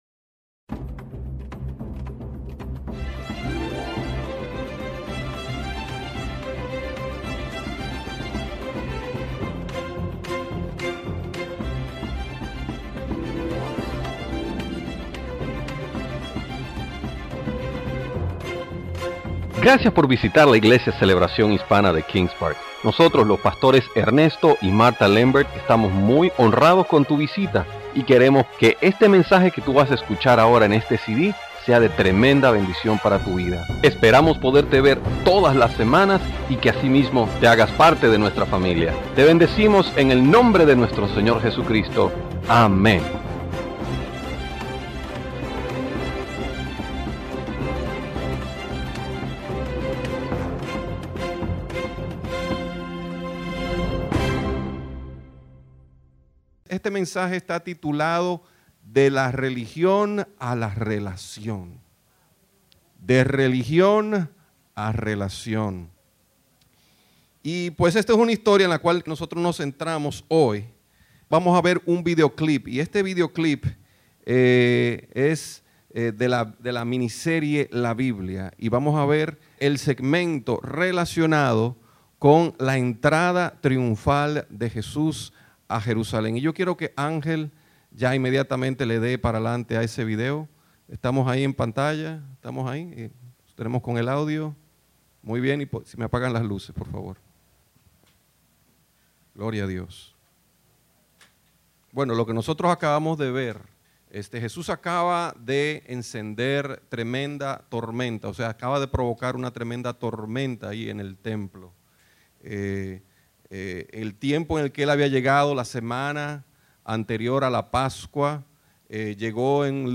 Predicadores